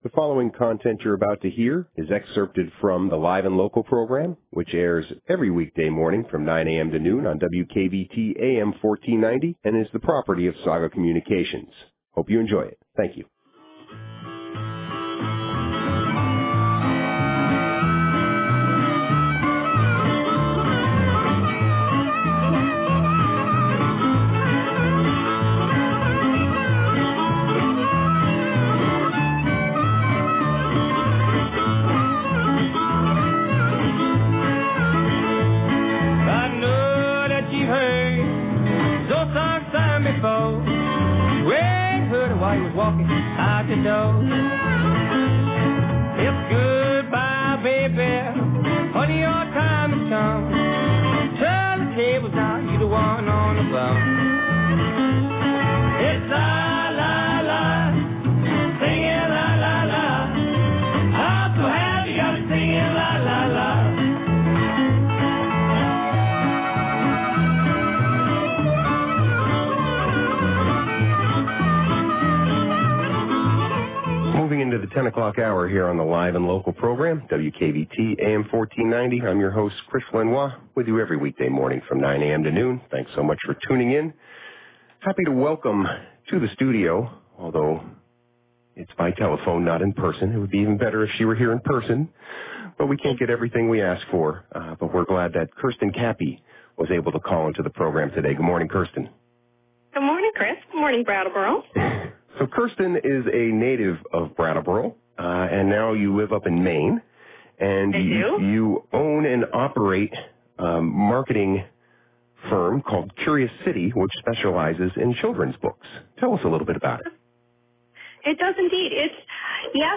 WKVT’s Live & Local